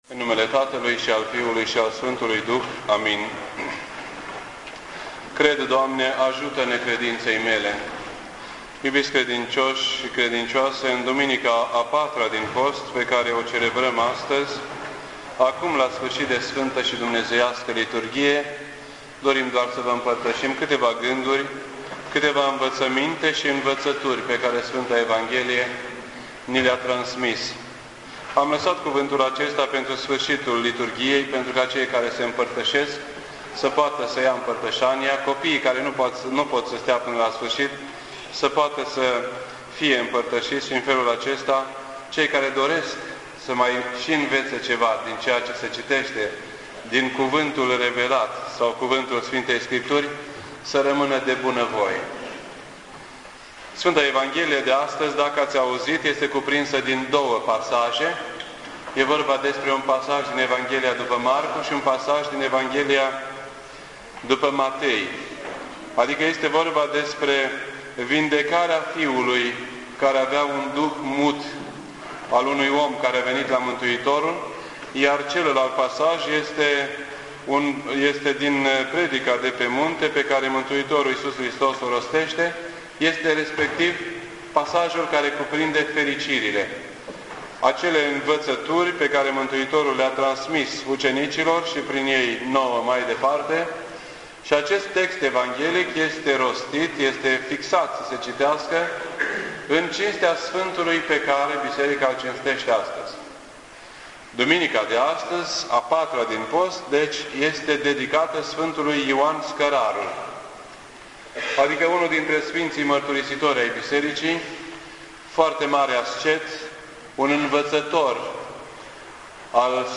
Tags: fericire, fiul lunatic, post-4, predica munte